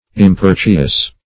Search Result for " importuous" : The Collaborative International Dictionary of English v.0.48: Importuous \Im*por"tu*ous\, a. [L. importuosus; pref. im- not + portuosus abounding in harbors, fr. portus harbor.]